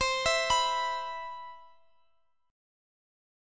Listen to E5/C strummed